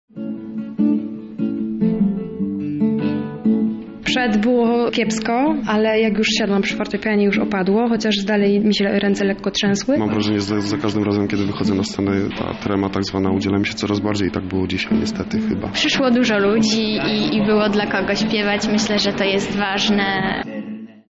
O swoich występach mówią uczestnicy